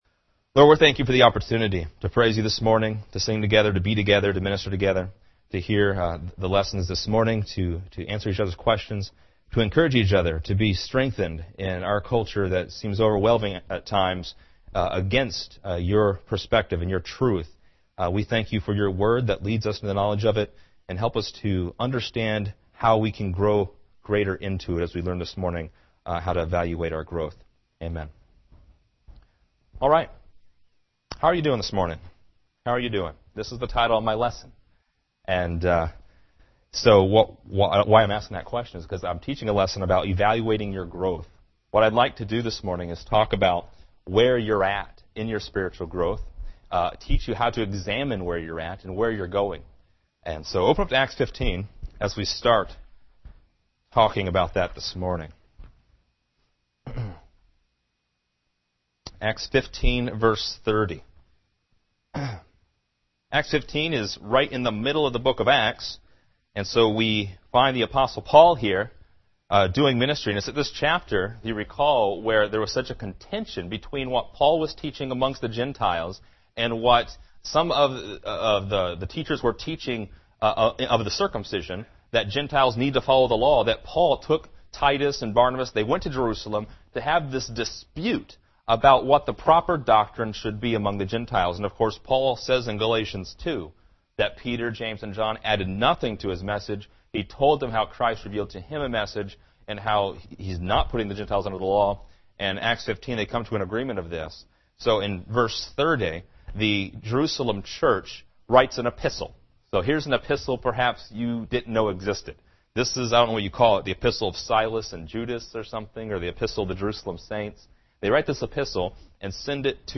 Description: In Acts 15, Paul determined to visit the bretheren in the churches he had started to see how they do: with the doctrine he taught and with living it out in their lives. A spiritual checkup is part of the Pauline pattern. This lesson shows you how to do a self-check.